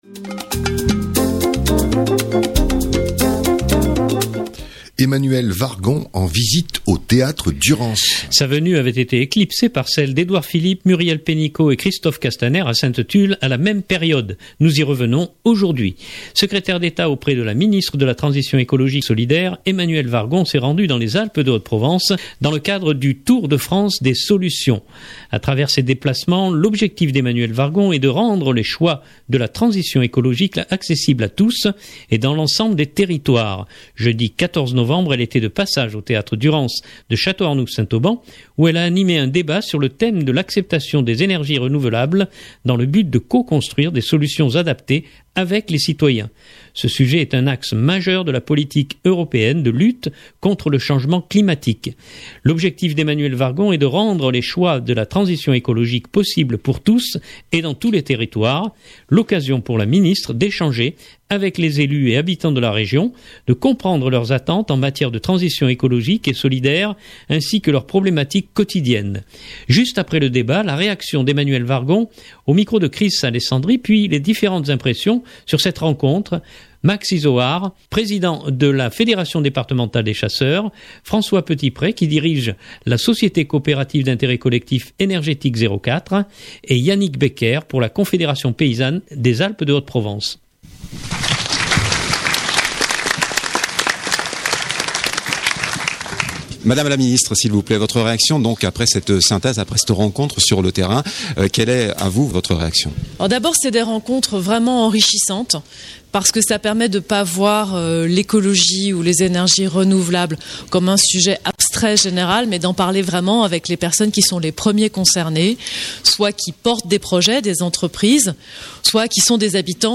Juste après le débat, la réaction d’Emmanuelle Wargon au micro